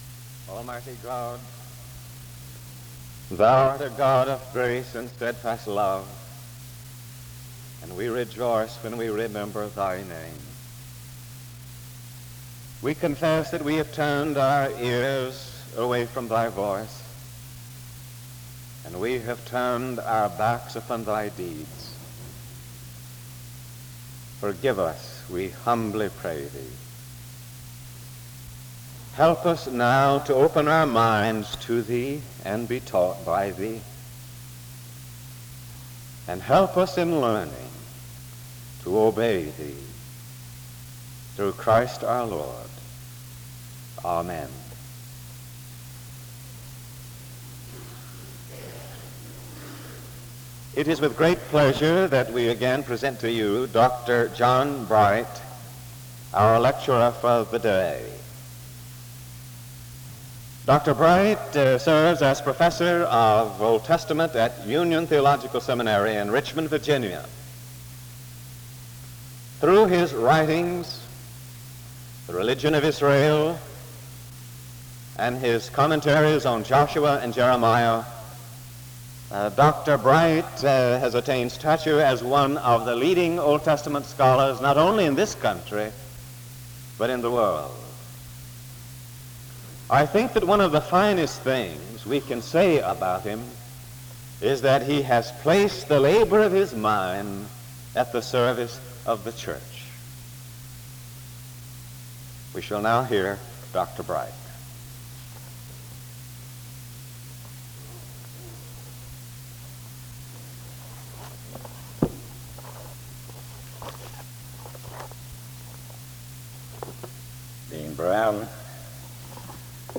File Set | SEBTS_Fall_Lecture_John_Bright_1967-09-29.wav | ID: b0da103b-7adc-4141-94fe-d80265dd5111 | Hyrax